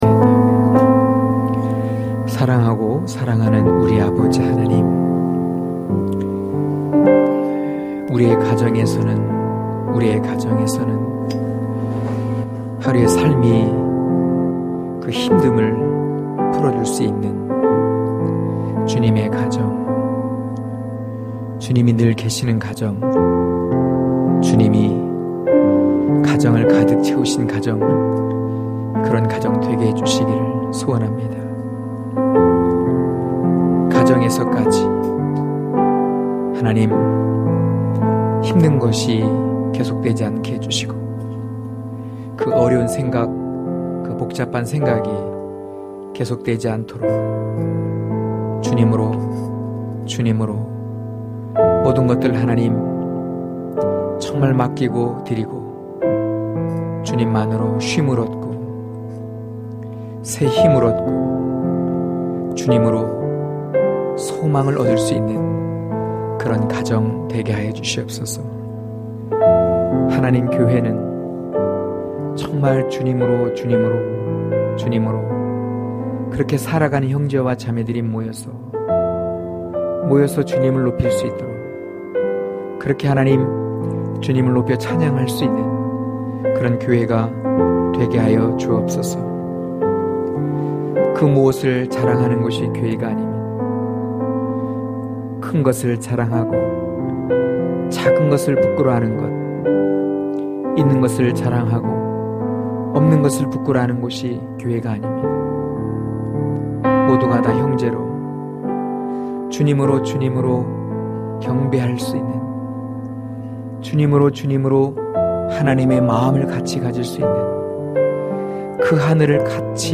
강해설교 - 4.율법+사랑=예수(요일2장7-17절)